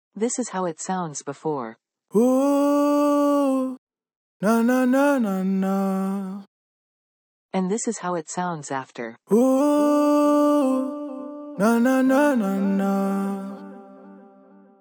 Vocal Tuning
autotune_serv_demo.mp3